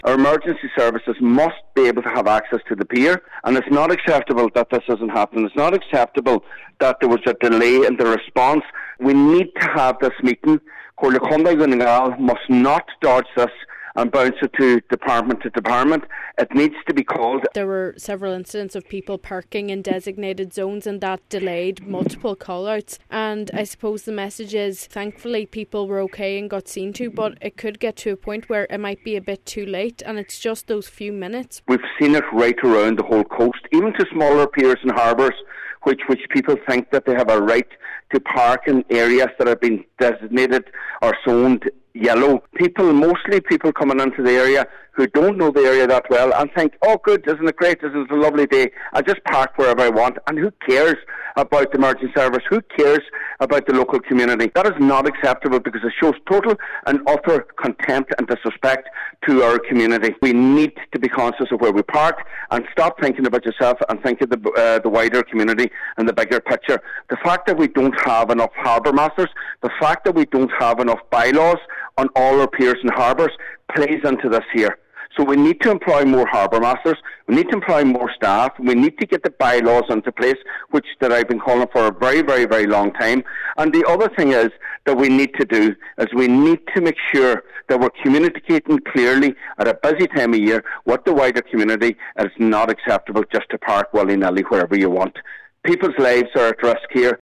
Cllr Micheal Choilm Mac Giolla Easbuig says something needs to be done or lives could be put at risk: